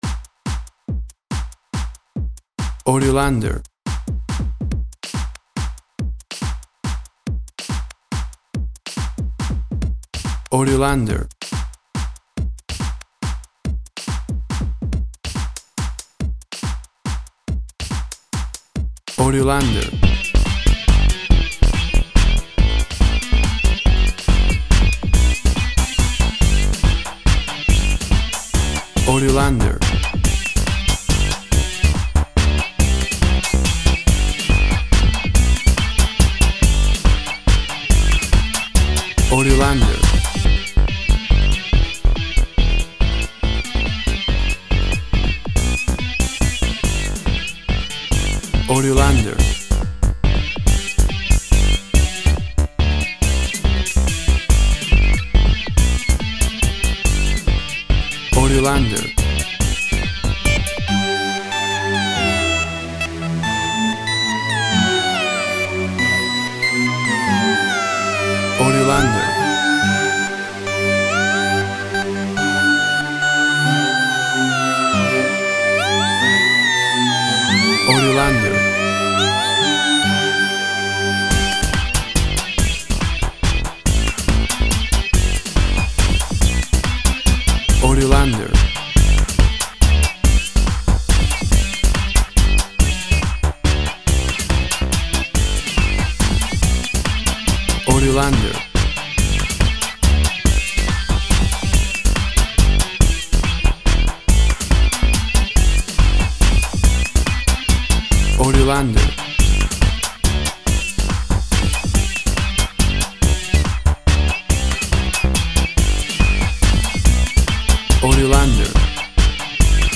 WAV Sample Rate 16-Bit Stereo, 44.1 kHz
Tempo (BPM) 120